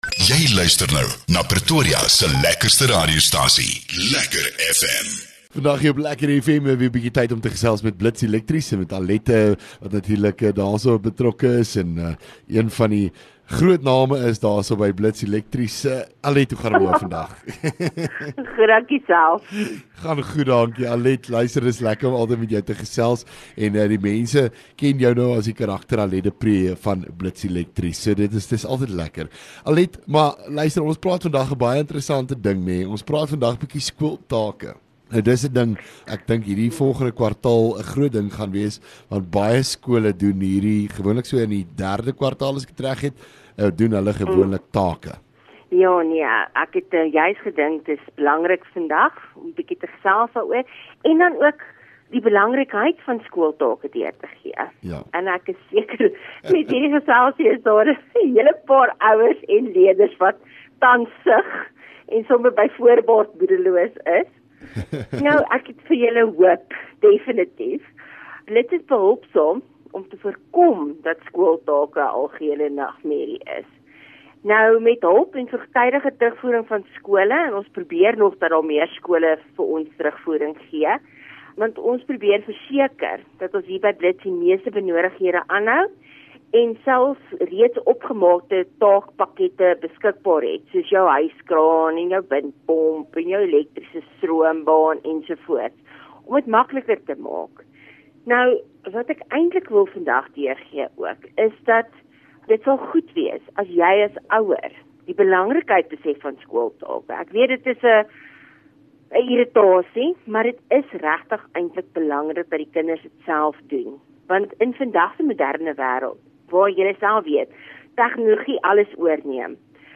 LEKKER FM | Onderhoude 31 Jul Blits Elektrisiëns